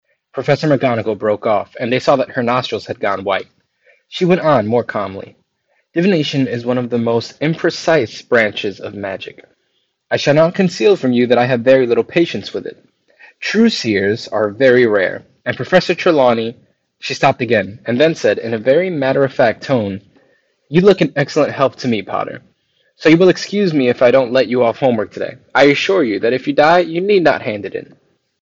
If you have a low voice a lot of it is going to be cut off by the microphone frequency response.
While these do have four noise cancelling microphones, the frequency drops off pretty hard at around 250Hz. In practice this means that the deeper notes of your voice won’t be as loud, which is good for speech intelligibility. However, it will make you sound a bit weird to the person on the other end—just like you would on any phone call.
Soundcore-Liberty-2-Pro-voice-sample.mp3